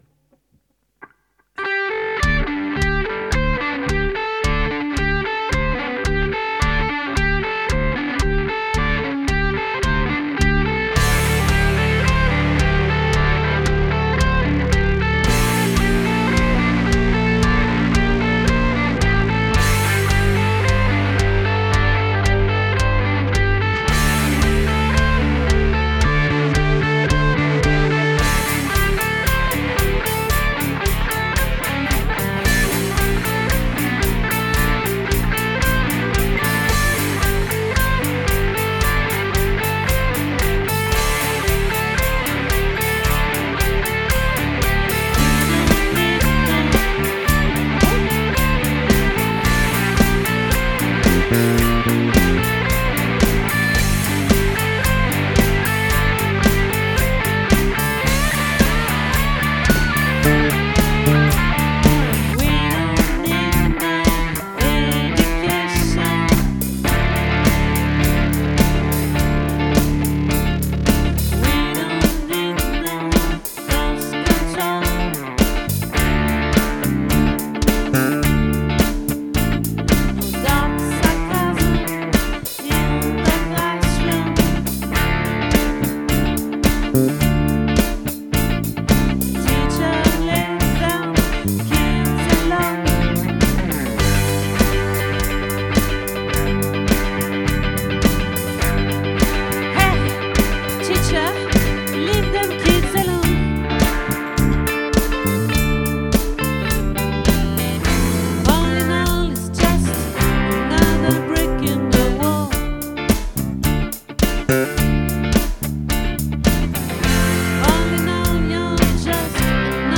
🏠 Accueil Repetitions Records_2025_03_03